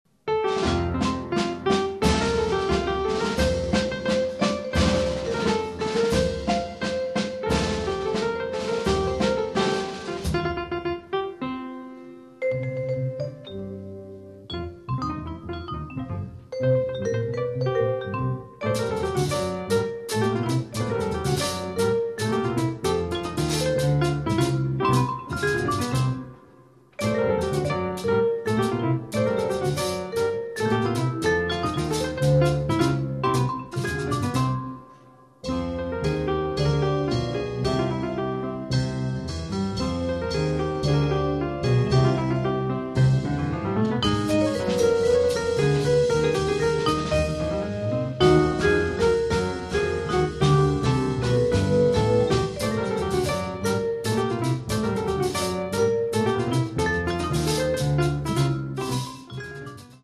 vibrafono
pianoforte
contrabbasso
batteria